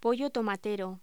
Locución: Pollo tomatero
voz